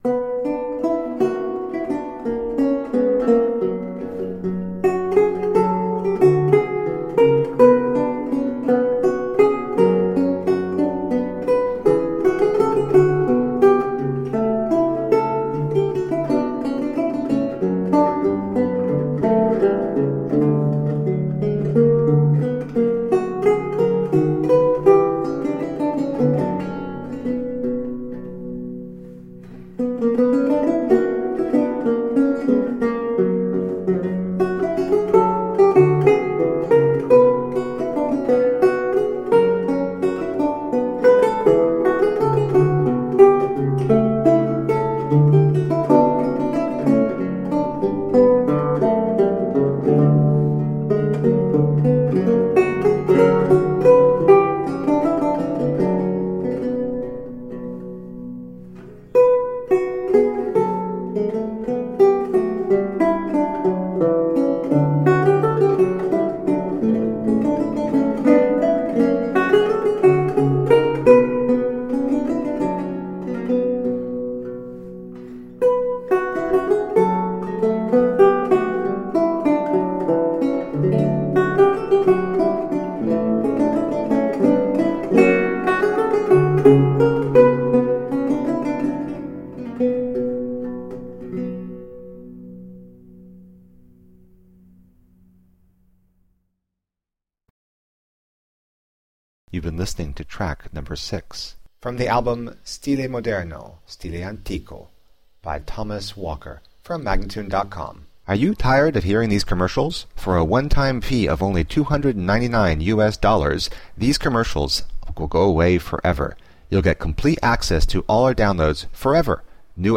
Lute music of 17th century france and italy.